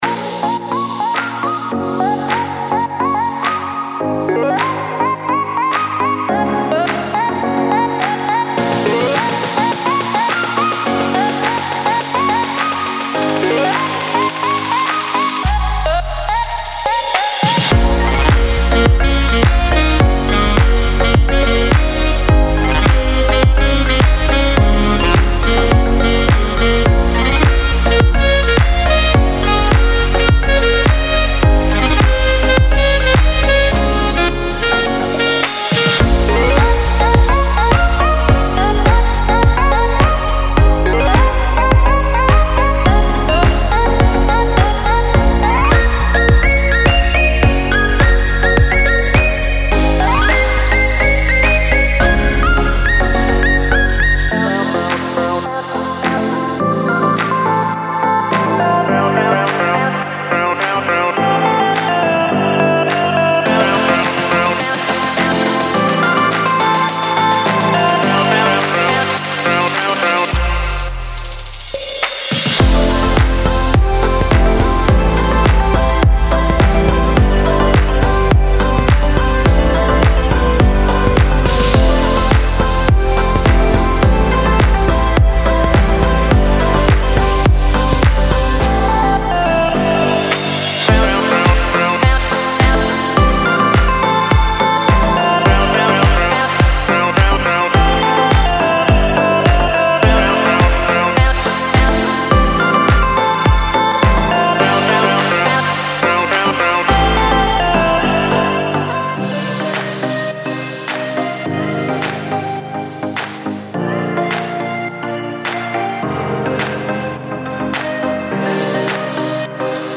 每个套件都包含所有乐器WAV和MIDI文件，例如钢琴，和弦，顶级旋律，鼓声循环，人声合唱循环和FX，具有最大的灵活性。
您在演示中听到的所有声音都将包括在内，并等待在下一个音乐作品中使用。
·42个旋律循环（贝司，和弦，钢琴和顶级旋律）